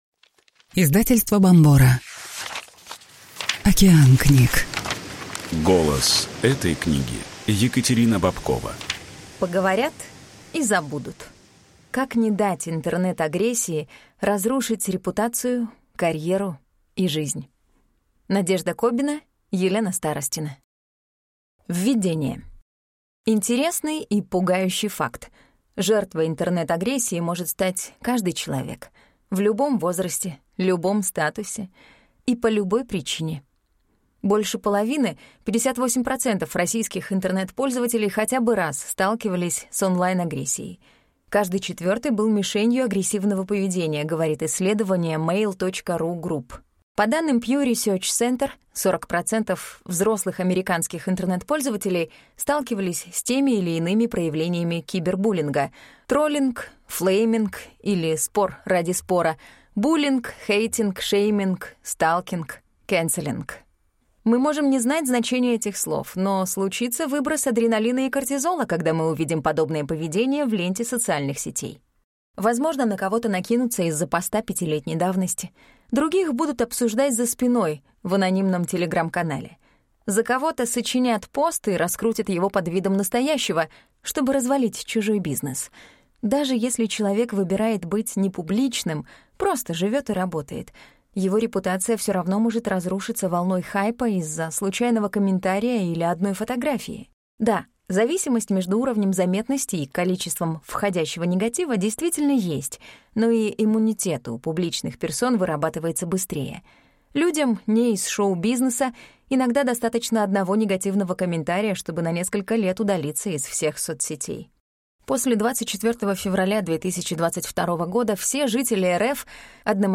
Аудиокнига «Повесть о любви и тьме».